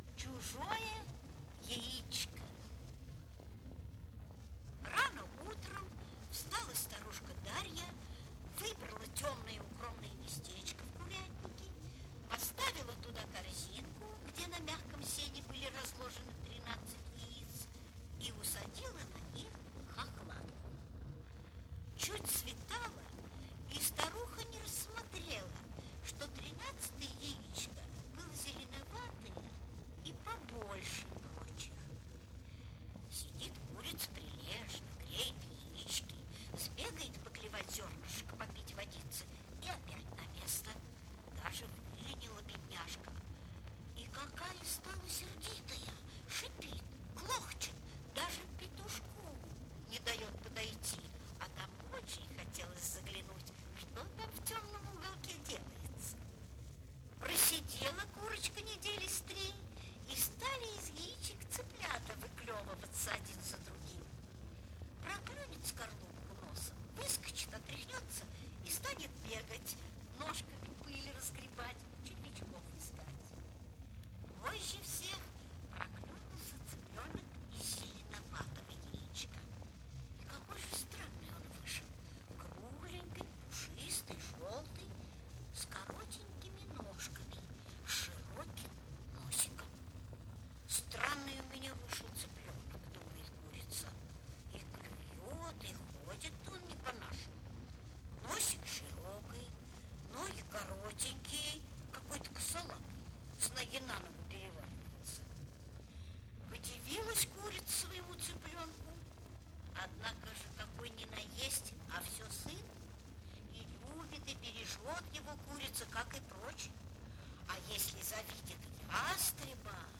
Чужое яичко - аудиосказка К.Д. Ушинского